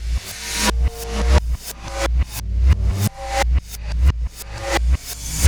Black Hole Beat 18.wav